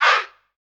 Vox
Chant Hey 003.wav